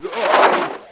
Heroes3_-_Vampire_-_DeathSound.ogg